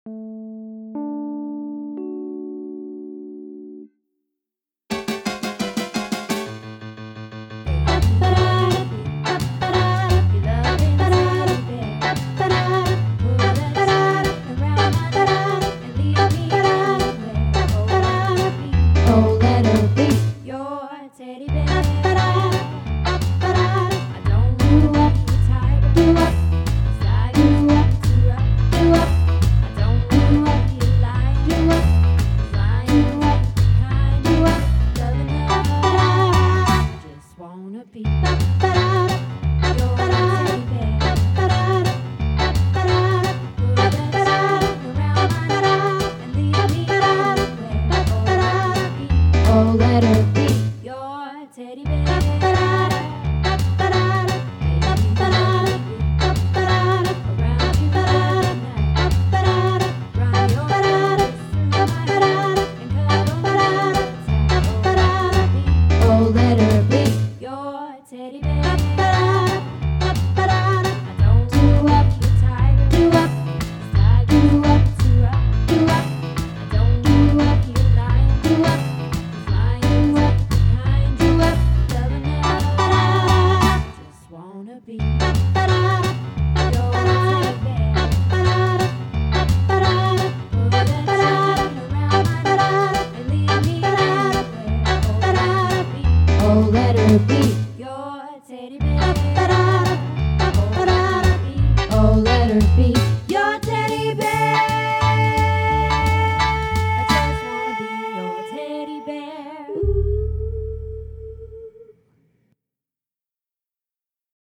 Teddy Bear - Sop